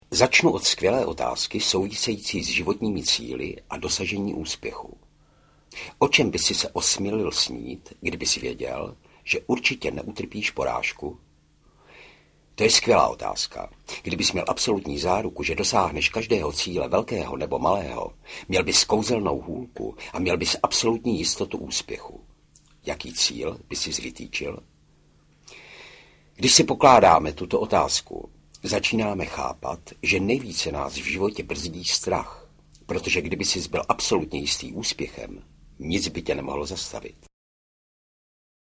Sebevědomí okamžitě audiokniha
Ukázka z knihy